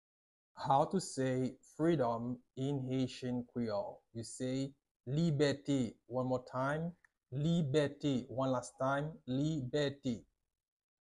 How to say "Freedom" in Haitian Creole - "Libète" pronunciation by a native Haitian tutor
“Libète” Pronunciation in Haitian Creole by a native Haitian can be heard in the audio here or in the video below:
How-to-say-Freedom-in-Haitian-Creole-Libete-pronunciation-by-a-native-Haitian-tutor.mp3